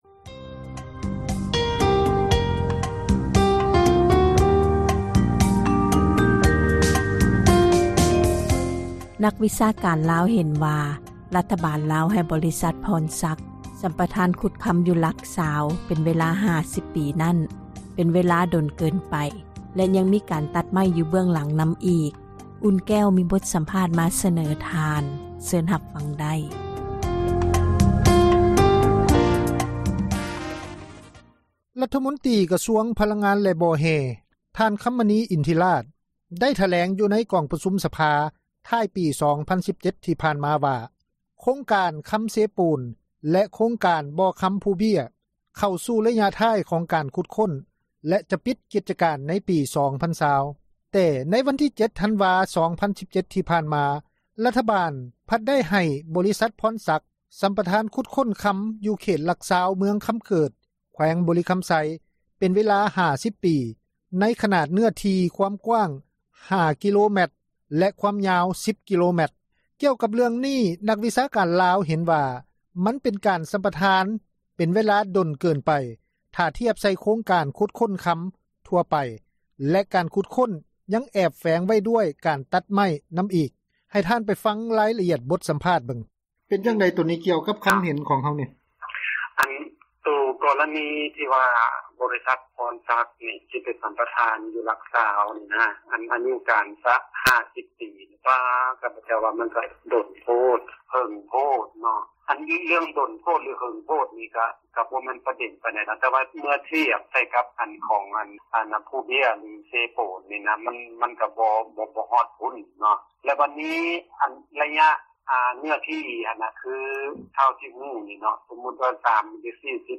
ກ່ຽວກັບເຣື້ອງນີ້ ນັກວິຊາການລາວ ເຫັນວ່າ ມັນເປັນການສັມປະທານເປັນເວລາດົນເກີນໄປ ຖ້າທຽບໃສ່ໂຄງການຂຸດຄົ້ນຄຳທົ່ວໄປ ແລະ ການຂຸດຄົ້ນຄຳຍັງແອບແຝງໄວ້ດ້ວຍ ການຕັດໄມ້ນຳອີກ. ໃຫ້ທ່ານ ໄປຟັງລາຍລະອຽດການສັມພາດ:…………………………………